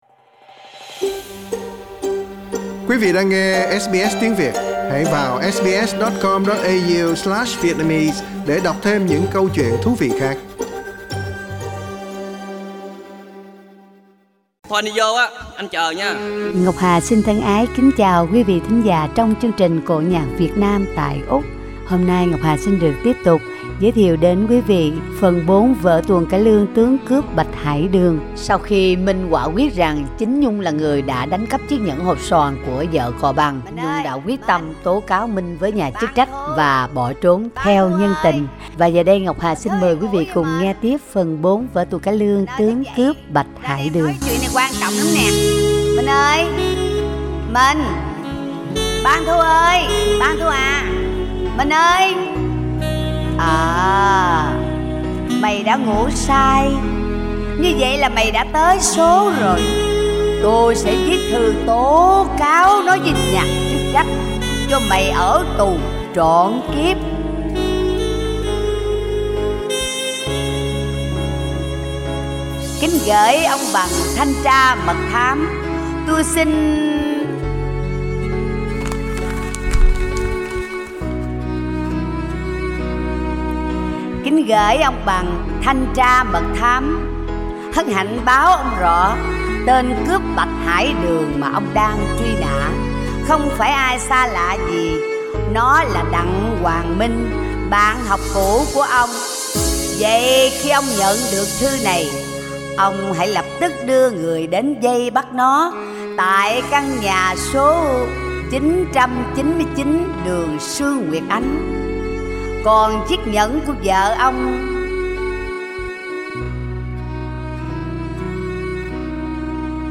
Vở cải lương 'Bạch Hải Đường' phần 4 Source